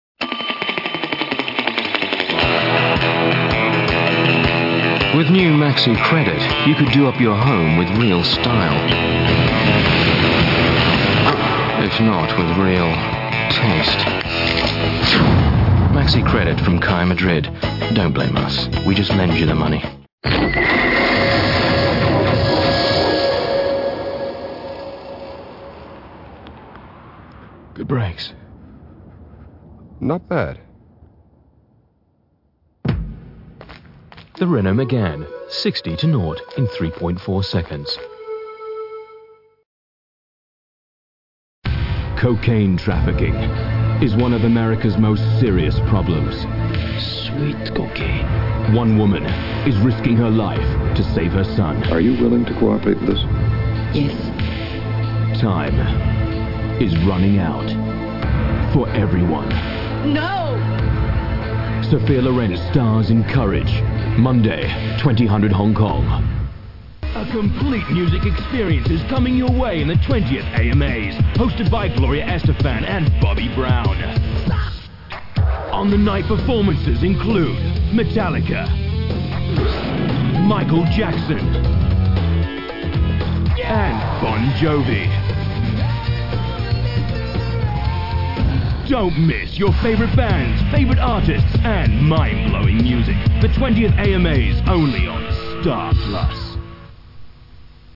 Brisbane